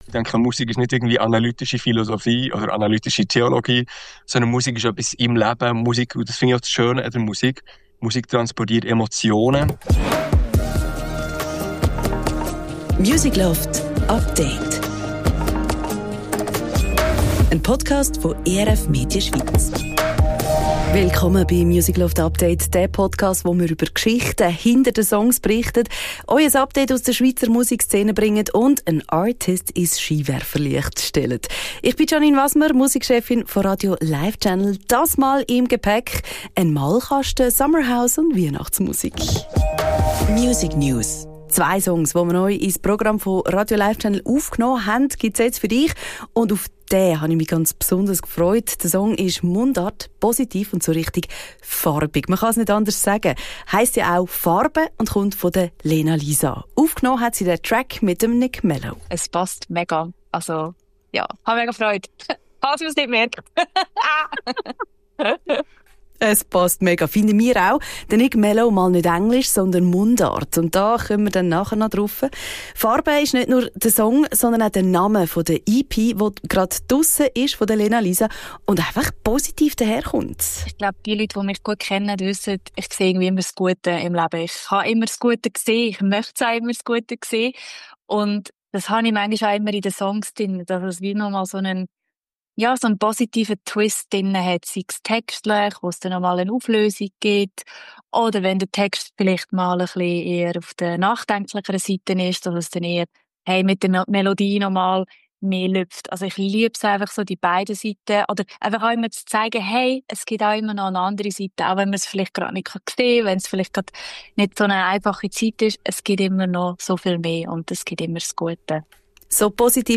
Beschreibung vor 4 Monaten Wenn es draussen so kalt und grau ist, dann packen wir doch unseren Malkasten aus, und bepinseln uns die Welt ein bisschen bunter: Zum Beispiel mit dem tiefgründigen Mundart-Song «Farbe», mit Summer-House, der tatsächlich auch in den Winter passt und mit Weihnachtsmusik, die schon grosse Vorfreude auf die festliche Zeit macht. Ausserdem lernen wir einen Musiker mit zwei Doktortiteln kennen und einen Schweizer Track, der das Thema «vorschnelles Urteilen» besingt.